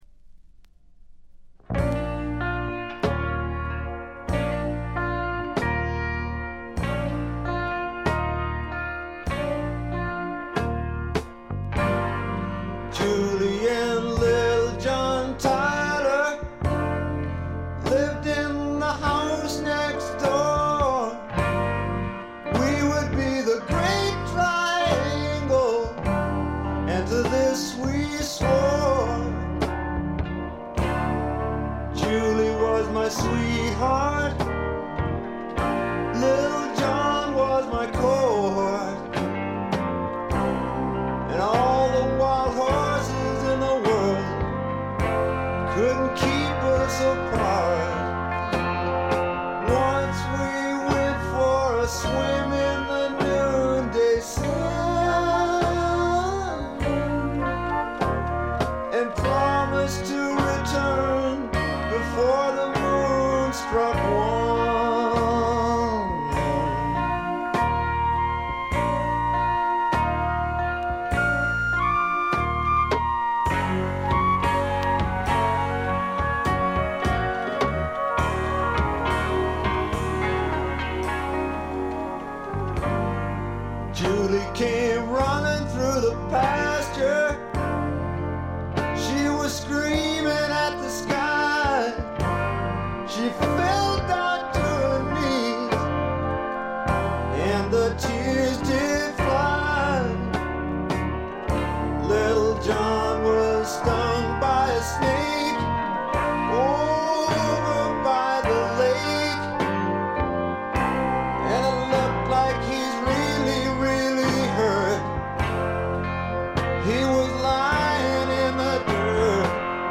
部分試聴ですが、ごくわずかなノイズ感のみ。
試聴曲は現品からの取り込み音源です。